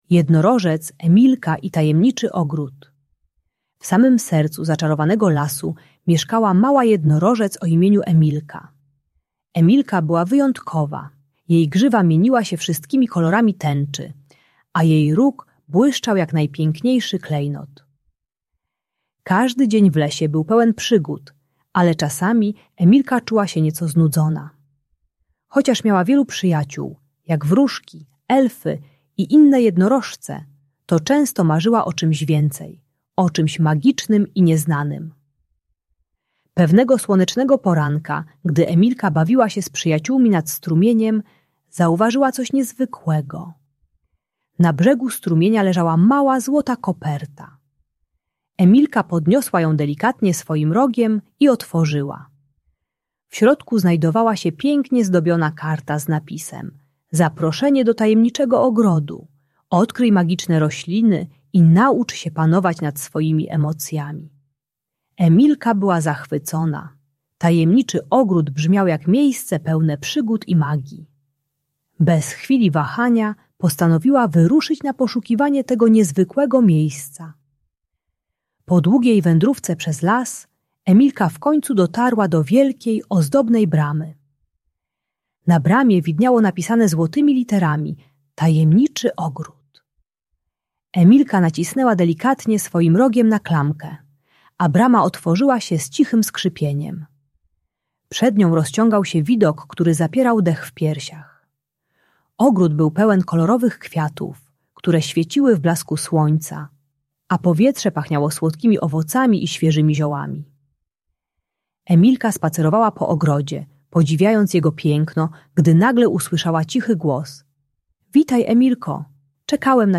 Jednorożec Emilka i Tajemniczy Ogród - Bunt i wybuchy złości | Audiobajka